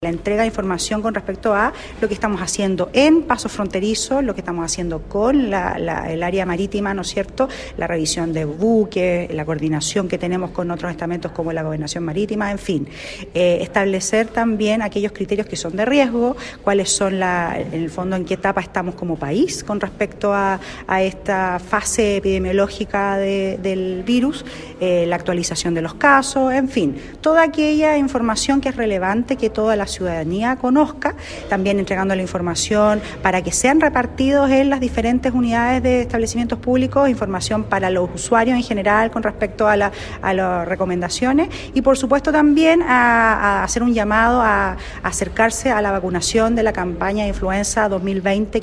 También hay prevención en los puertos, aeropuertos y pasos fronterizos, dijo la autoridad de salud, donde se detalló la normativa que se está aplicando para la entrada de personas venidas de otros países.